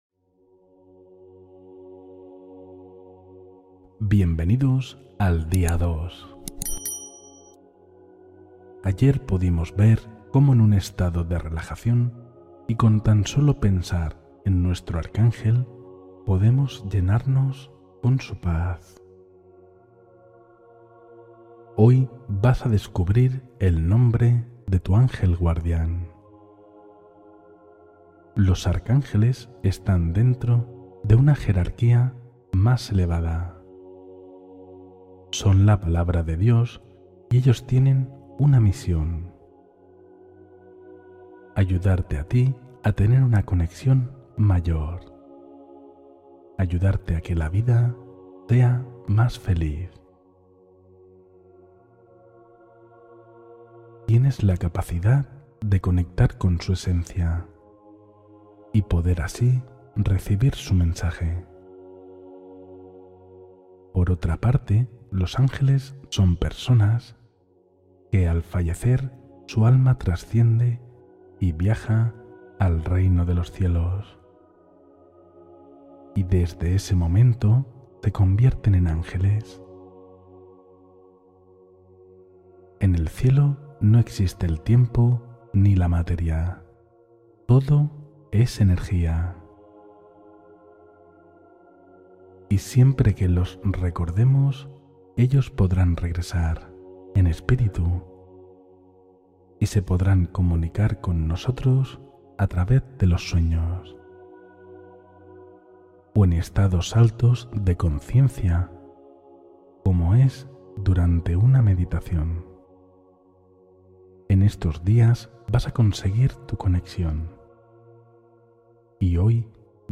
Siente la protección de tu ángel de la guarda – Meditación guiada Día 2